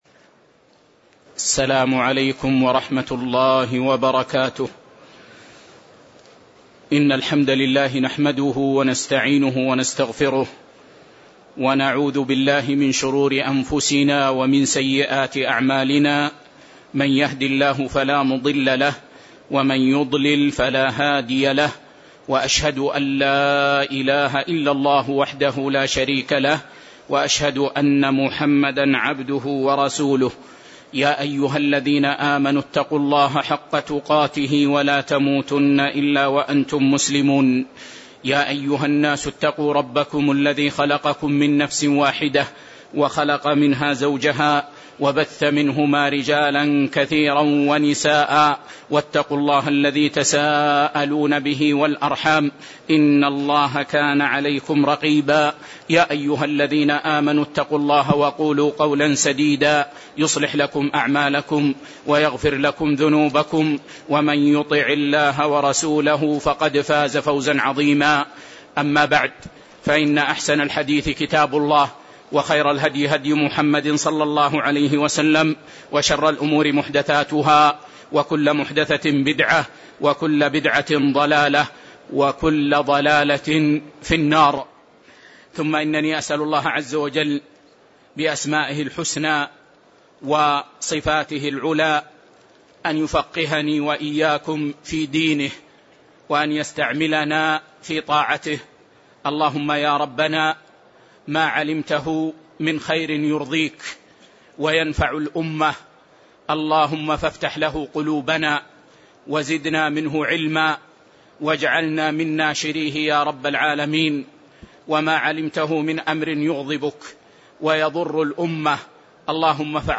تاريخ النشر ٦ شعبان ١٤٣٧ هـ المكان: المسجد النبوي الشيخ